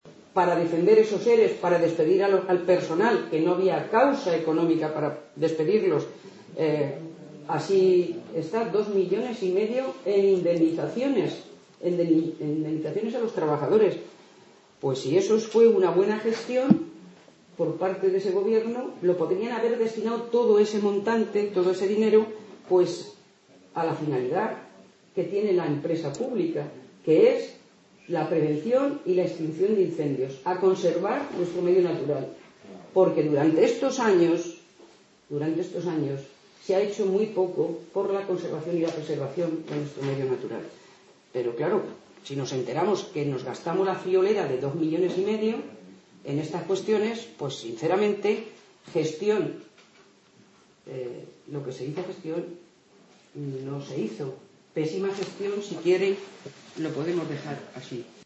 La diputada Carmen Torralba asegura que la gestión del anterior gobierno con esta empresa pública fue desastrosa "despidieron a más de 500 trabajadores, recortaron los servicios de prevención de nuestros montes y encima no ahorraron ni un duro"
Cortes de audio de la rueda de prensa